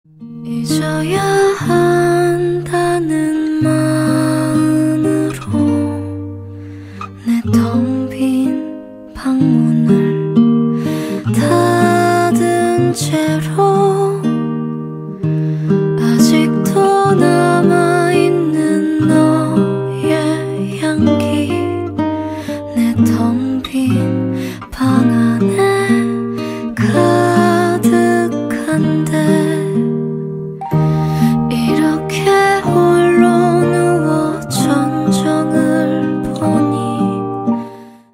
이건 도입부